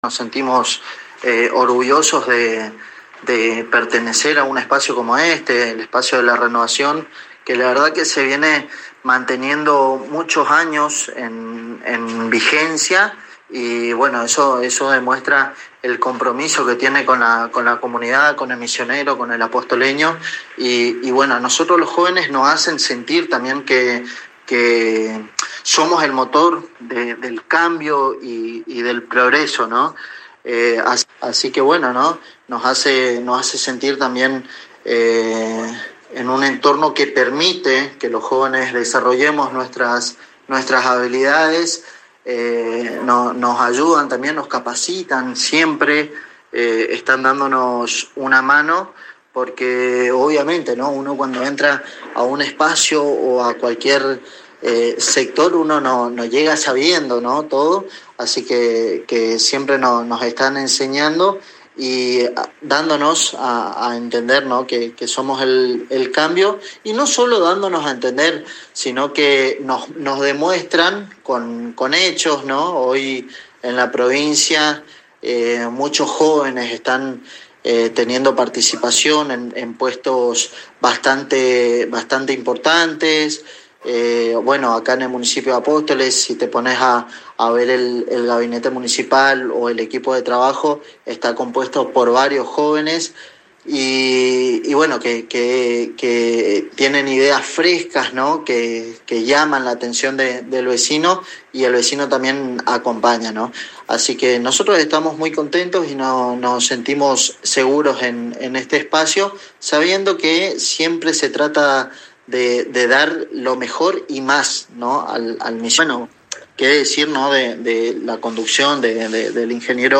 en diálogo exclusivo con la ANG a través del Programa «La Buena Noticia»